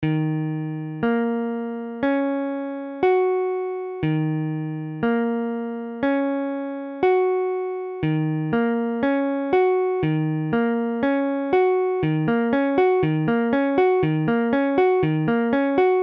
Tablature Ebm7.abcEbm7 : accord de Mi bémol mineur septième
Mesure : 4/4
Tempo : 1/4=60
A la guitare, on réalise souvent les accords de quatre notes en plaçant la tierce à l'octave.
Le doigté est ainsi plus aisé et l'accord sonne mieux.
Ebm7.mp3